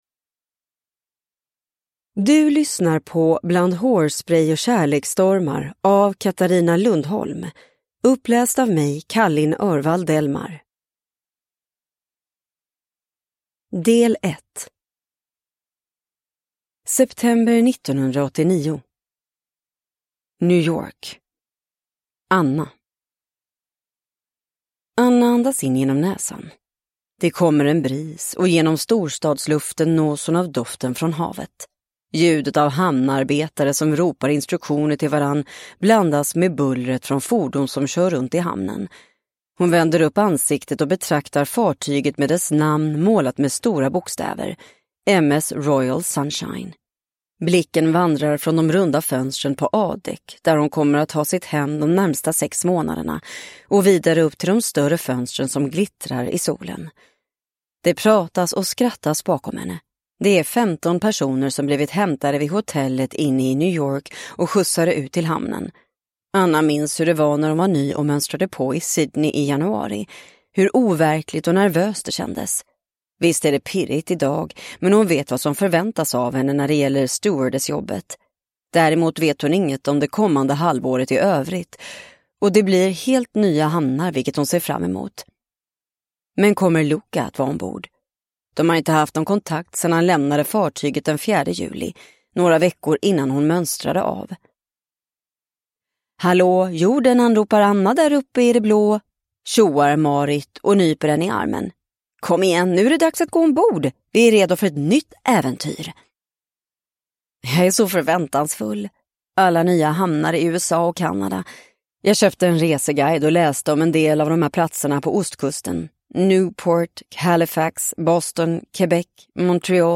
Bland hårsprej och kärleksstormar (ljudbok) av Katarina Lundholm